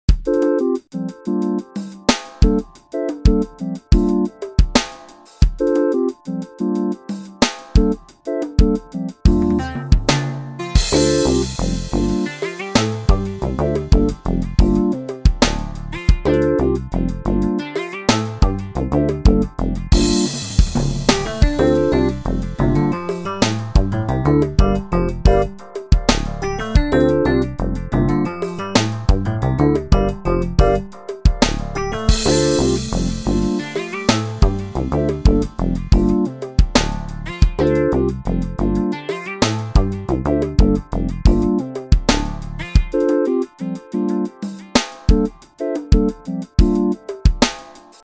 Genre Jazz